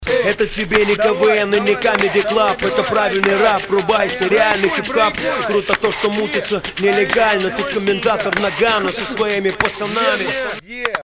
кто нибудь, вытащите из бэков битбокс, плюс в репу и баальшое спасибо обеспечены!)
как он делает так глухо, не подскажешь? дефект микро или он как то в руки битует? biggrin
там не Pf,a Psh (вместо ф делается глухой ш) поэтому так и глухо звучит
и часть бита так звучит Brr Psh(t) tb - tb Psh(t)